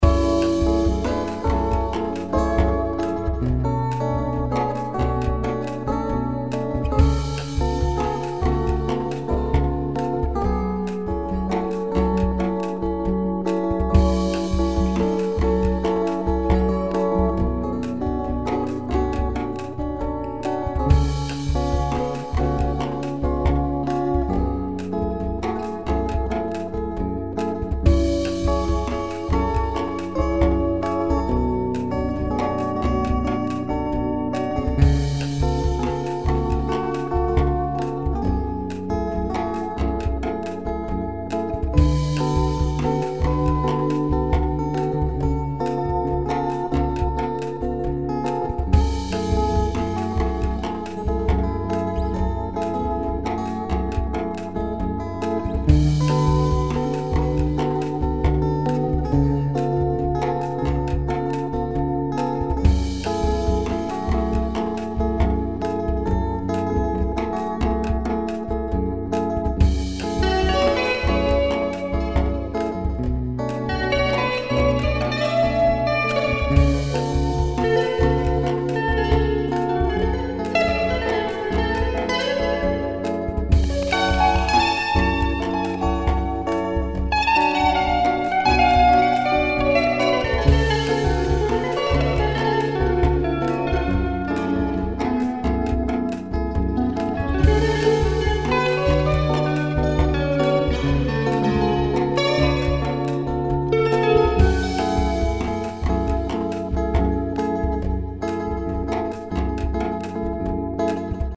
Ascolta un mio solo su una song
in E minore:
Em Am7 B7 Em